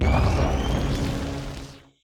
Minecraft Version Minecraft Version 1.21.5 Latest Release | Latest Snapshot 1.21.5 / assets / minecraft / sounds / mob / illusion_illager / prepare_blind.ogg Compare With Compare With Latest Release | Latest Snapshot